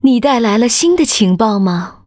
文件 文件历史 文件用途 全域文件用途 Dana_tk_04.ogg （Ogg Vorbis声音文件，长度2.1秒，103 kbps，文件大小：26 KB） 源地址:游戏语音 文件历史 点击某个日期/时间查看对应时刻的文件。